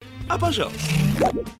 голосовые
из фильмов